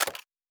pgs/Assets/Audio/Sci-Fi Sounds/Weapons/Weapon 01 Foley 3.wav at master
Weapon 01 Foley 3.wav